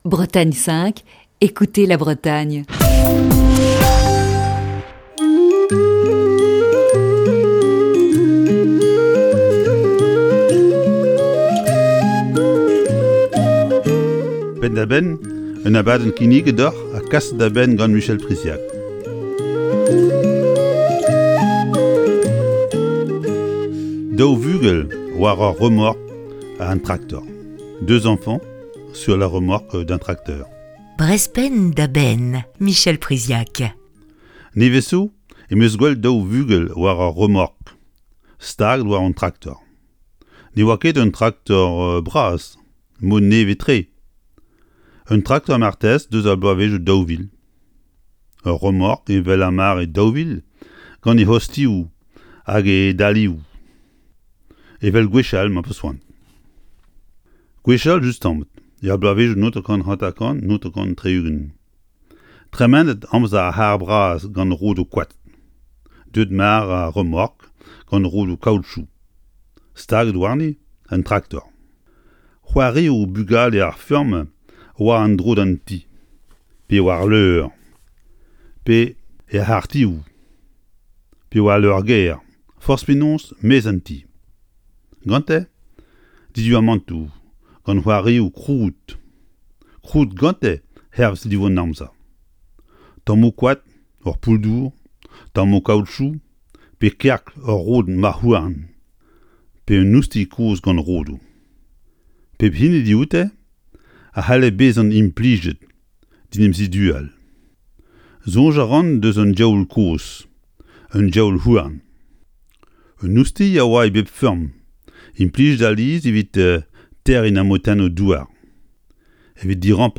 Chronique du 17 novembre 2020.